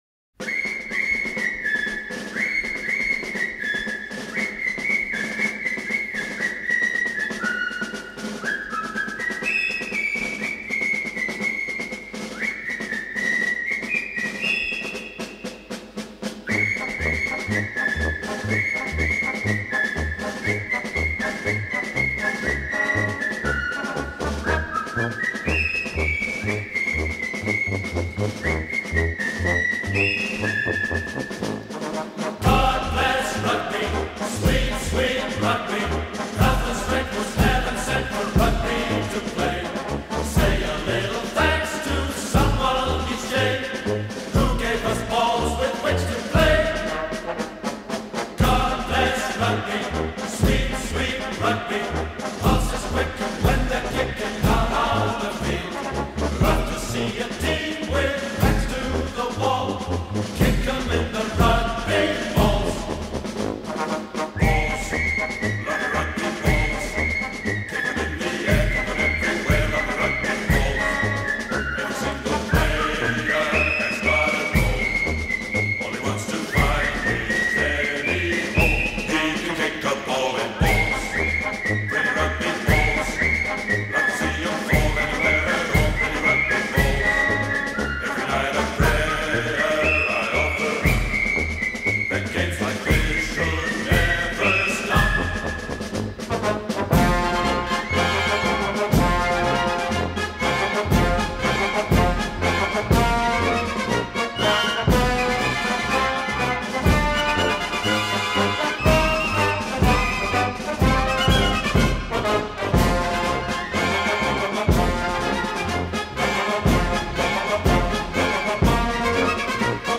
112 R God bless rugby (Le mur de l'Atlantique) C. BOLLING /L. DELBECQ Marche 🖼